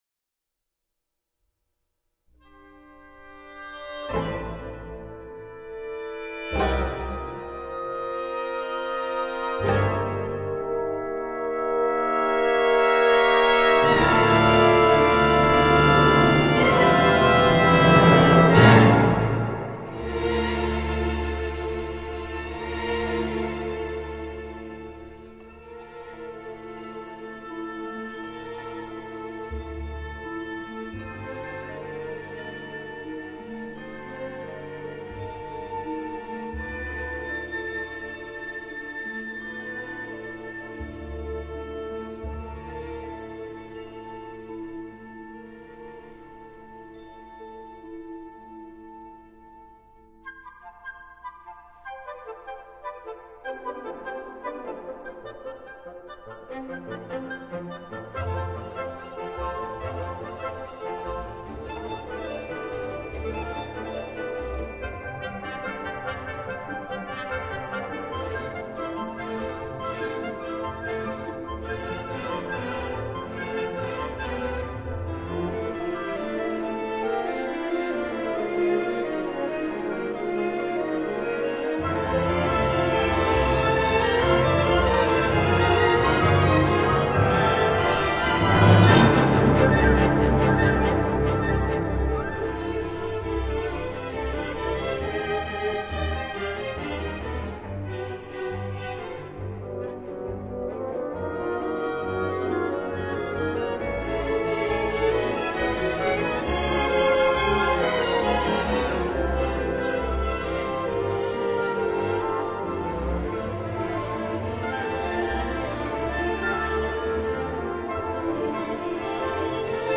Regard sur l’exploration entre musique et art visuel : entrevue avec Françoise Sullivan
Dans son atelier de Pointes-St-Charles, j’ai eu la chance de discuter avec l’illustre Françoise Sullivan, artiste phare, multidisciplinaire de la modernité, au Québec.